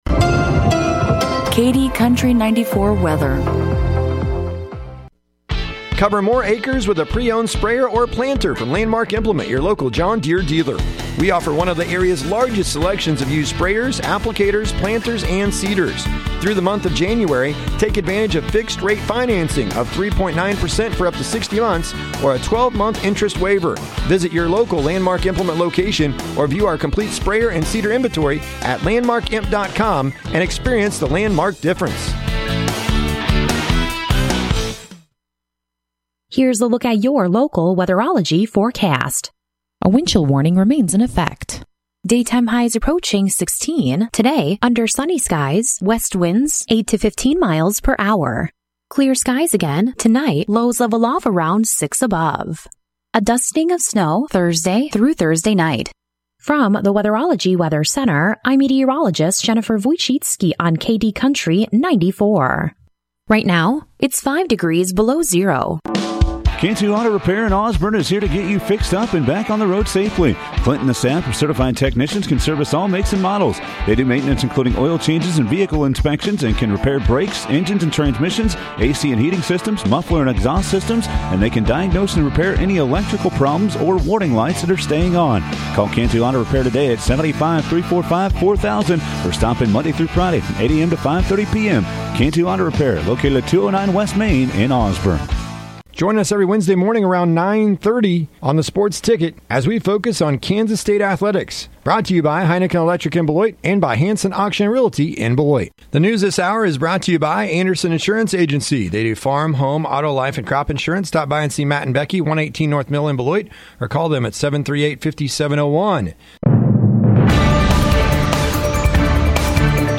KD Country 94 Local News, Weather & Sports – 1/16/2024